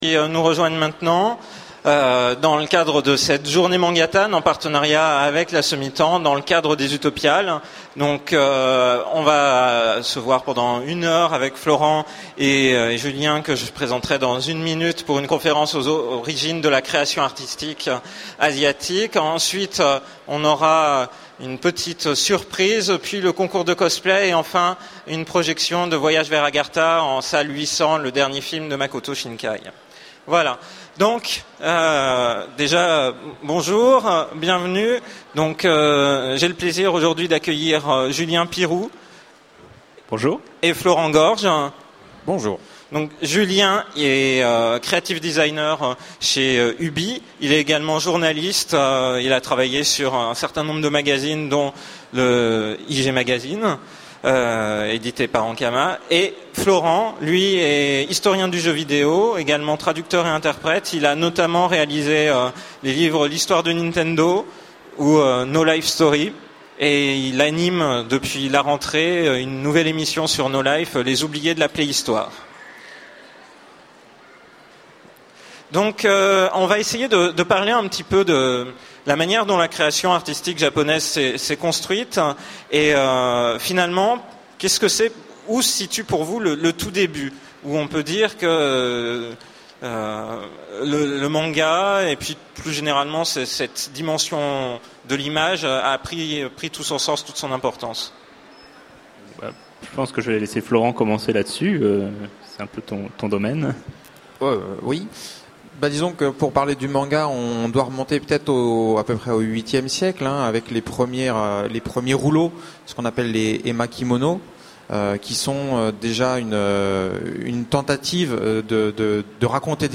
Utopiales 12 : Conférence Aux origines de la création asiatique